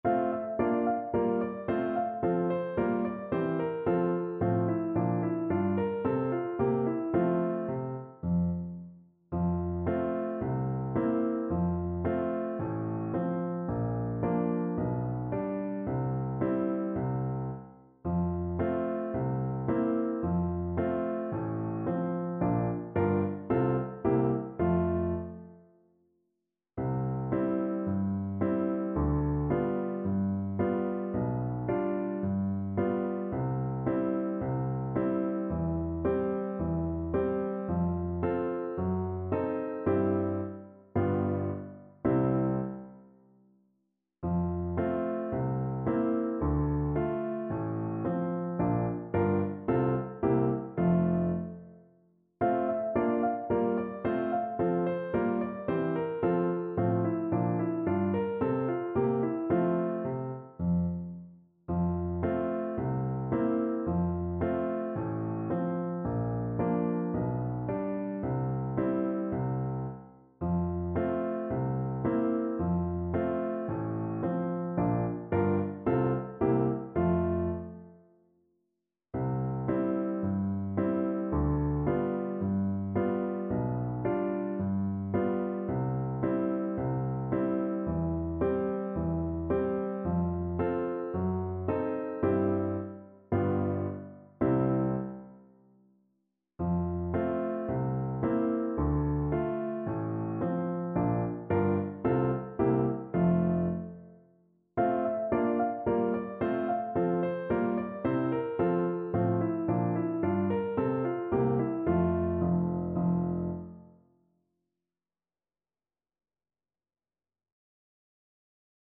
4/4 (View more 4/4 Music)
Allegro moderato =110 (View more music marked Allegro)
F3-C5
Classical (View more Classical Bass Voice Music)